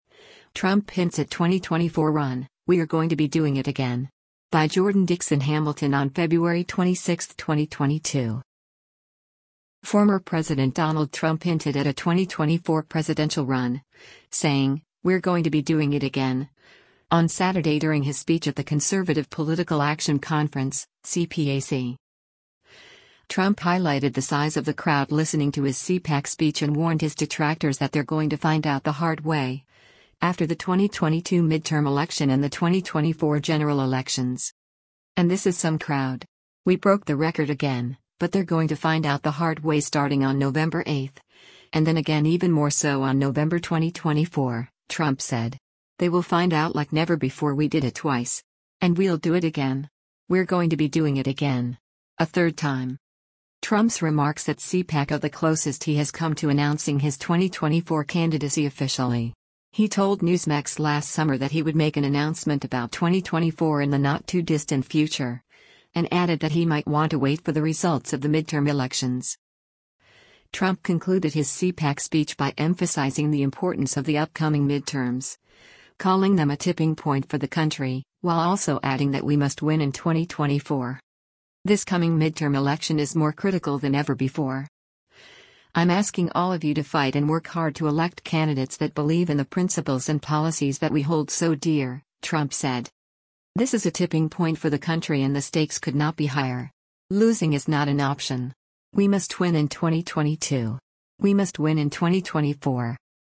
Former President Donald Trump hinted at a 2024 presidential run, saying, “We’re going to be doing it again,” on Saturday during his speech at the Conservative Political Action Conference (CPAC).
Trump highlighted the size of the crowd listening to his CPAC speech and warned his detractors that “They’re going to find out the hard way,” after the 2022 midterm election and the 2024 general elections.